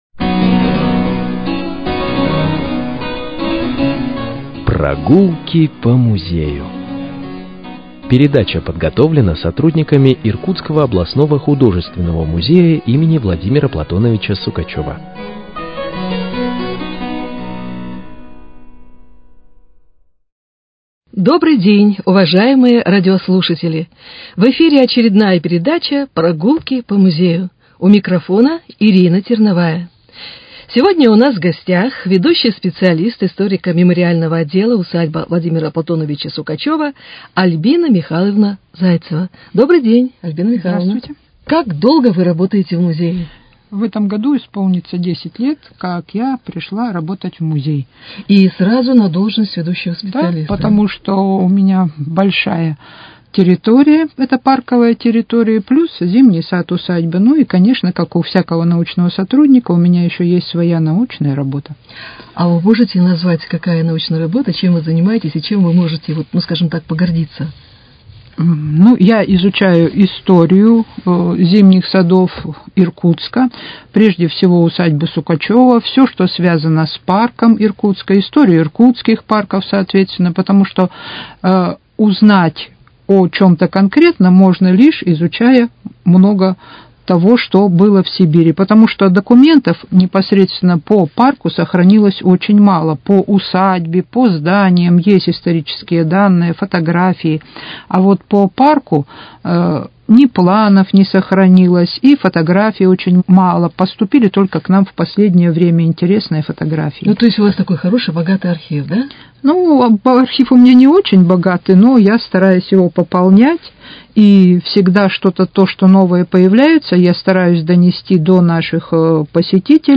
Прогулки по музею: Беседа